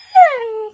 mob / wolf / whine.ogg
whine.ogg